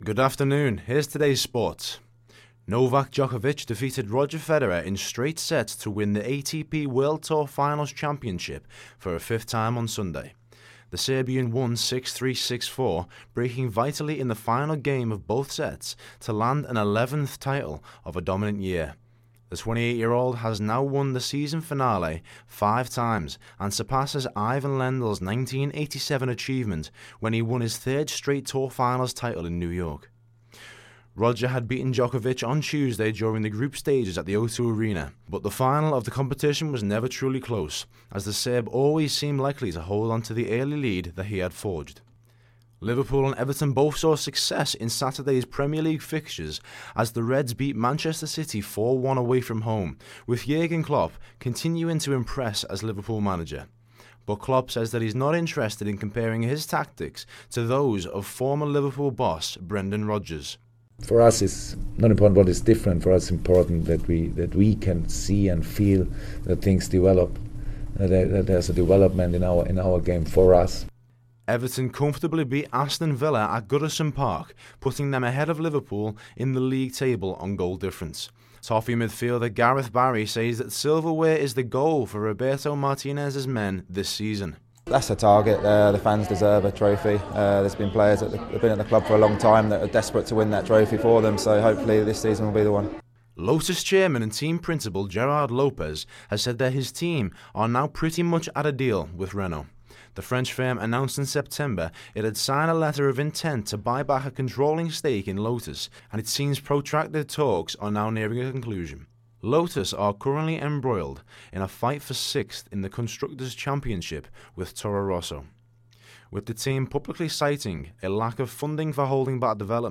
A round up of this afternoon's sports news for JMU Journalism Radio's 1pm bulletin.